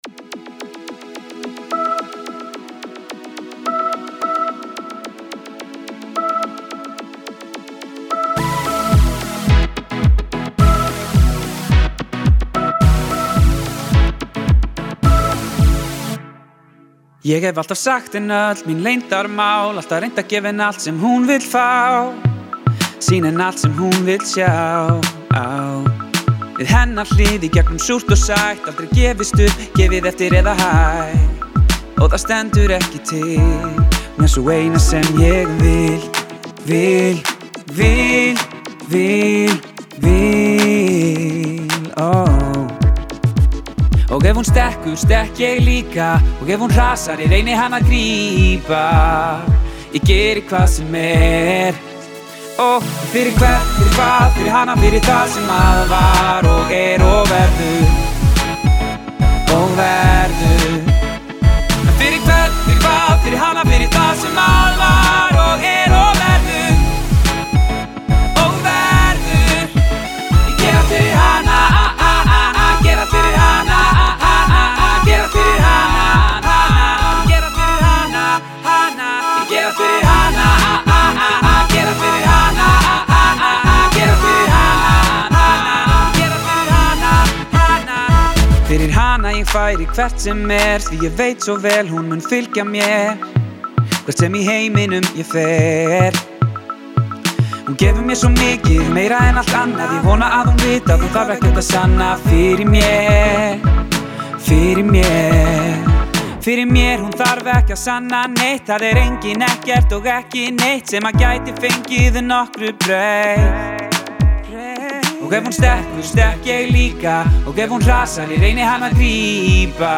has a more aggressive production to it